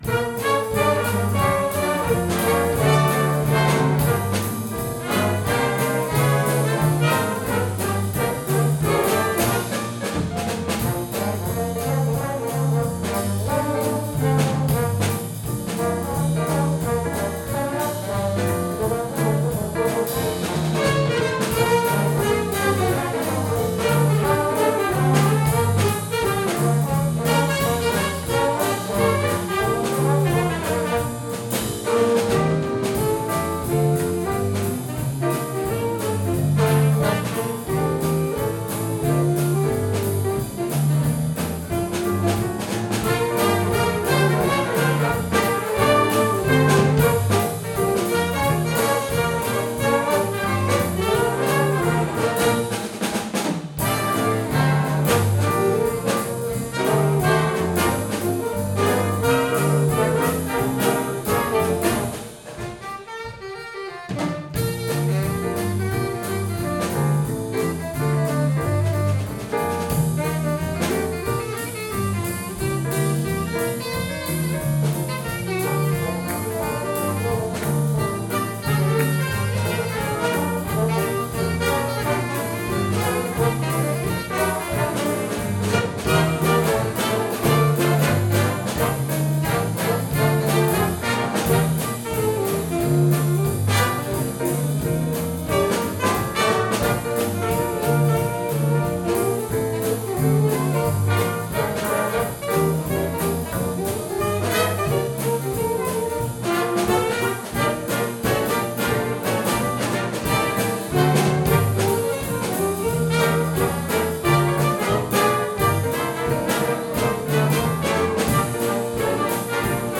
Big Band storband bröllop fest event party jazz